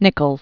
(nĭkəlz), Mike Originally Michael Igor Peschkowsky. 1931-2014.